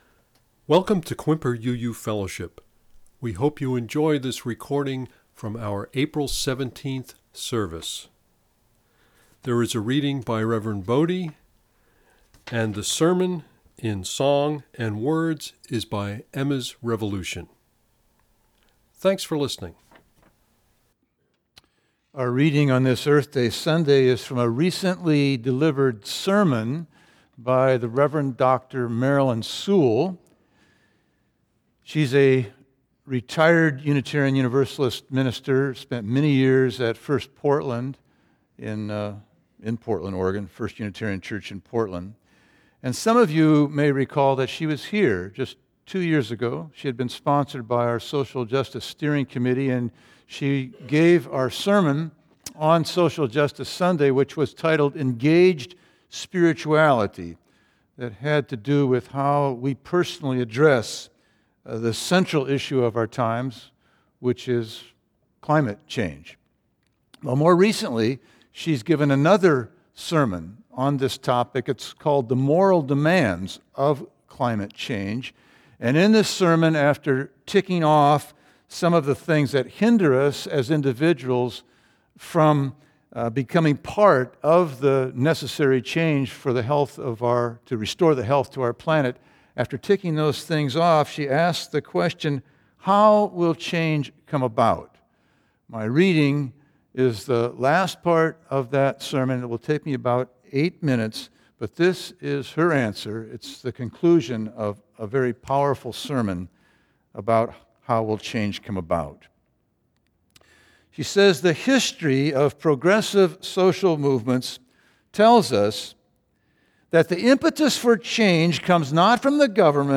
Our special guests were the performing duo and ecology activists Emma’s Revolution.
Click here to listen to the reading and sermon.